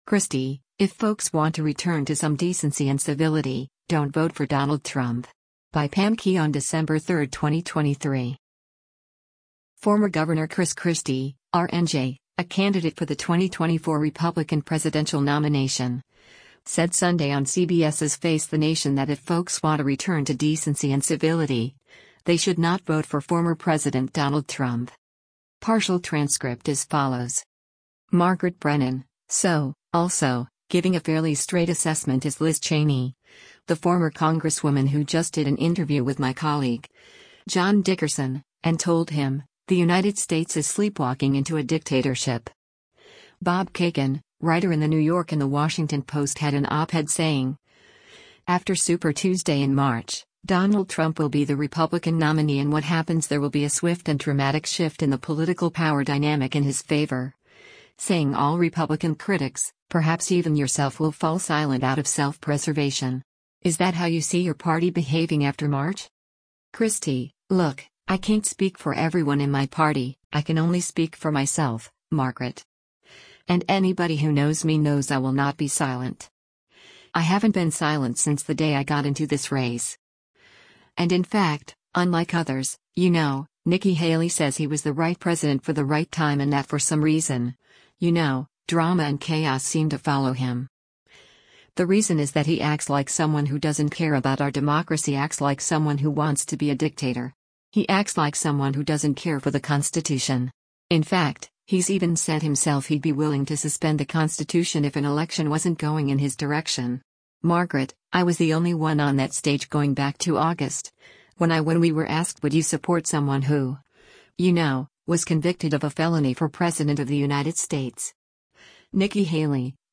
Former Gov. Chris Christie (R-NJ), a candidate for the 2024 Republican presidential nomination, said Sunday on CBS’s “Face the Nation” that if folks want a return to “decency and civility,” they should not vote for former President Donald Trump.